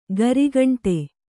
♪ garigaṇṭe